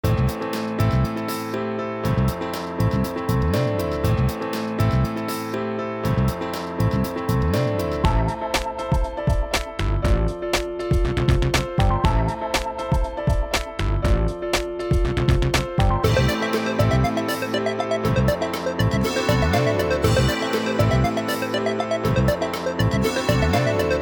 Жанр: Hip-Hop
Hip-hop Атмосферный 250 BPM